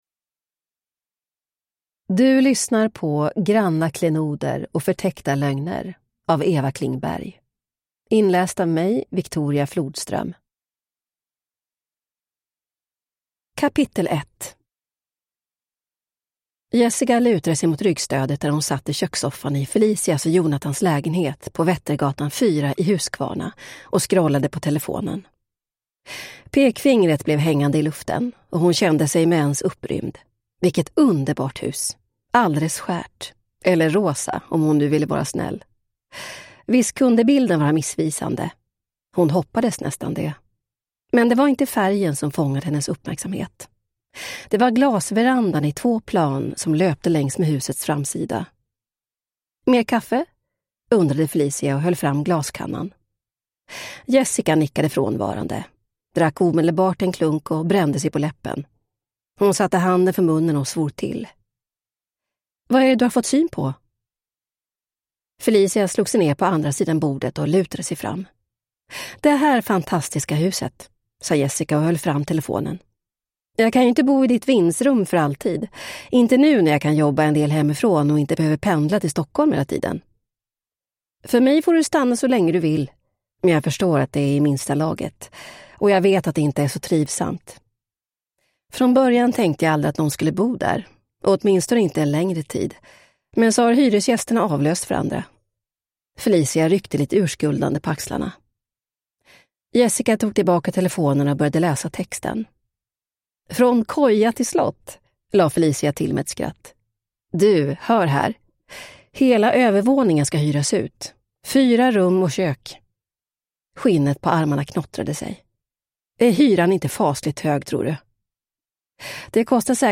Granna klenoder och förtäckta lögner / Ljudbok